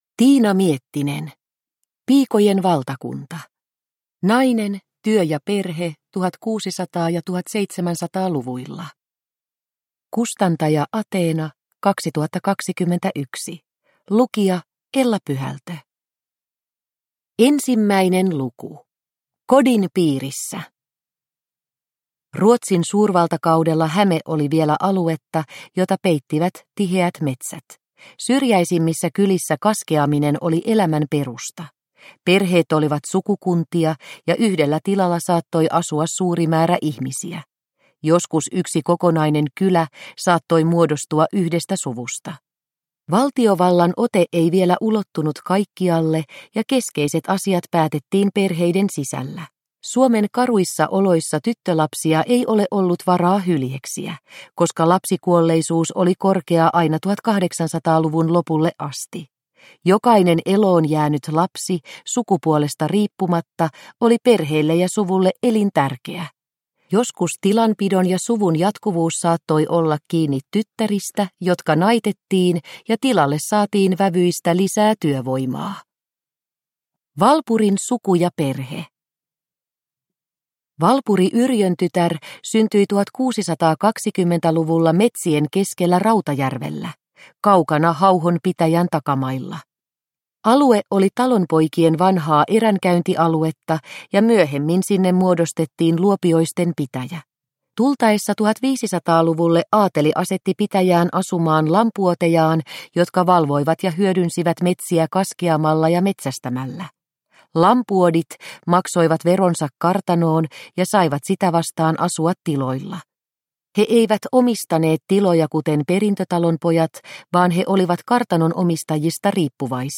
Piikojen valtakunta – Ljudbok – Laddas ner